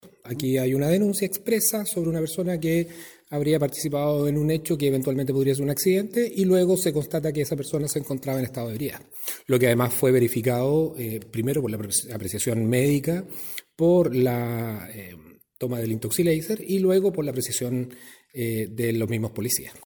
El persecutor dijo que se efectuó todo el procedimiento de rigor y en cada uno de los pasos que se siguieron, se confirmó que estaba conduciendo en estado de ebriedad, aunque declinó entregar el registro del Intoxileiser.